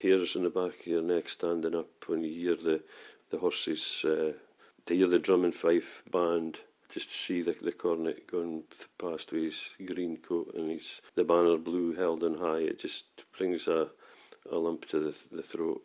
The Hawick Common Riding for 2015 got underway this morning with the chase and songs at the Hut.